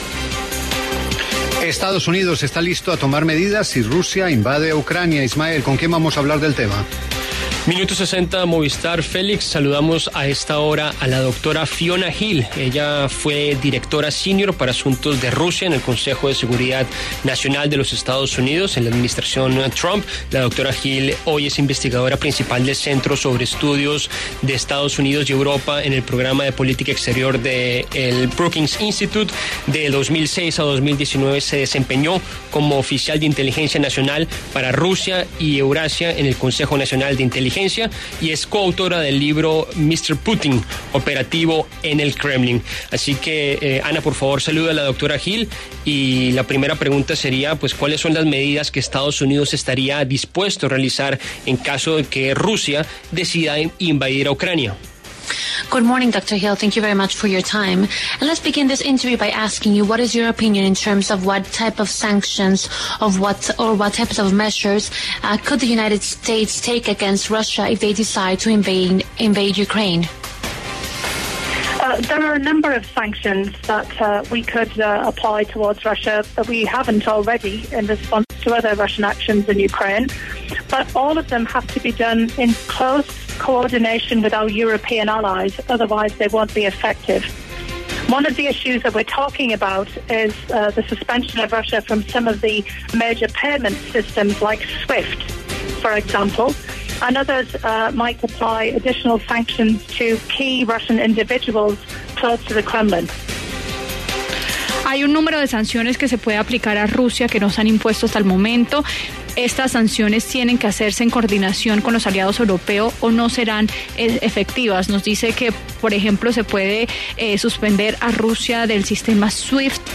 En el encabezado escuche la entrevista completa con Fiona Hill, exdirectora Senior para Asuntos de Rusia en el Consejo de Seguridad Nacional de Estados Unidos.